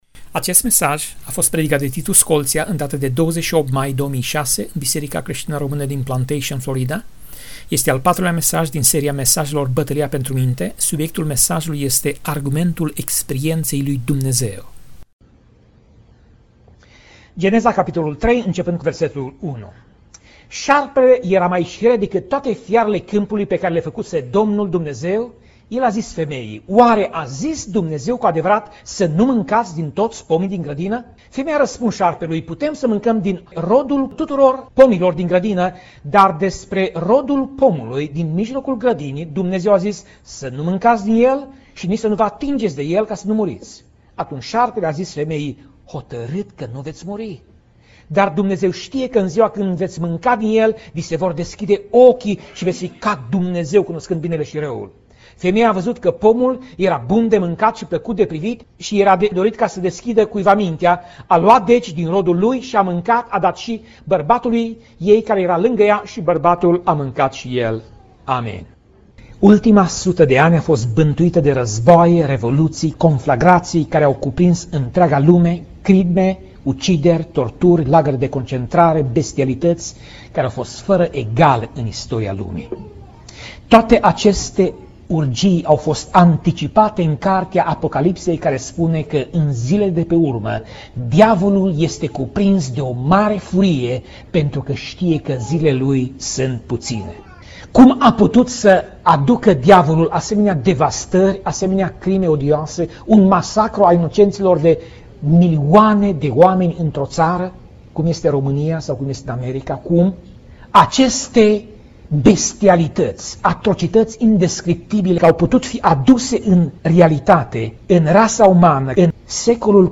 Pasaj Biblie: Ioan 1:43 - Ioan 1:51 Tip Mesaj: Predica